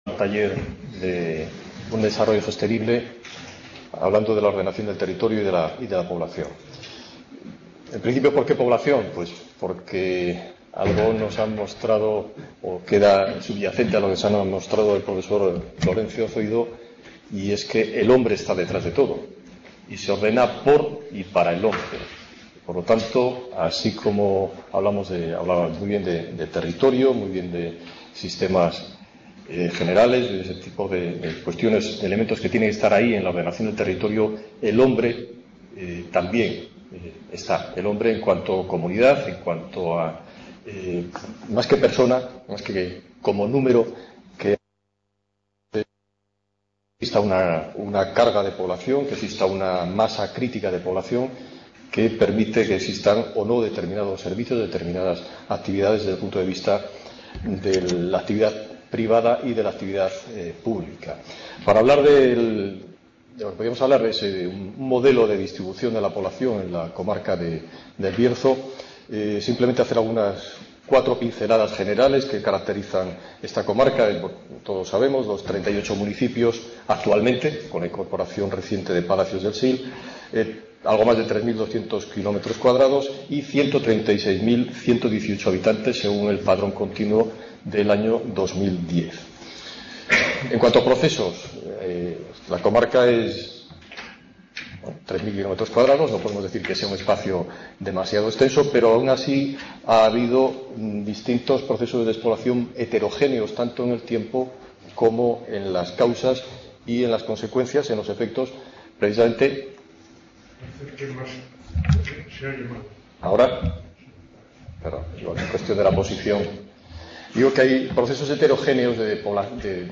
Panel de expertos - La población en el modelo…
4º Taller. Hacia un nuevo modelo territorial para El Bierzo